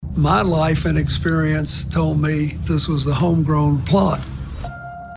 CLICK HERE to listen to words from Former President Bill Clinton.